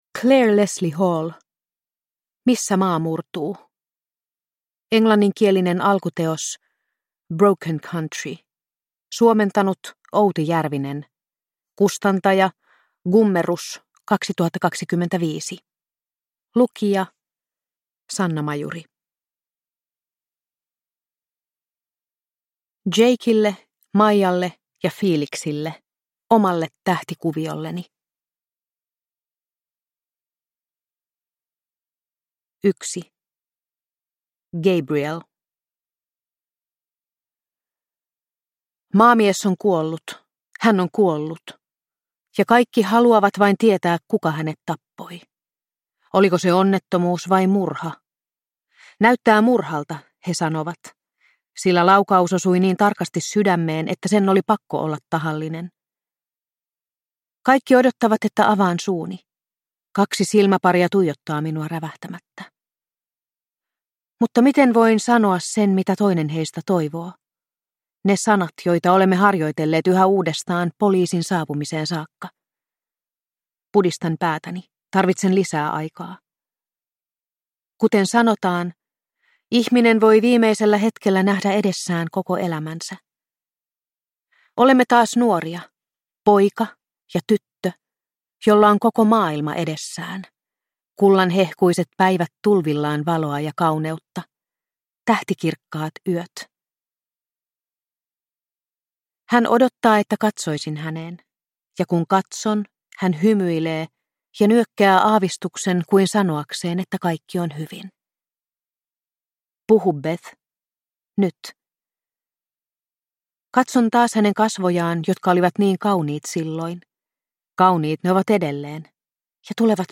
Missä maa murtuu (ljudbok) av Clare Leslie Hall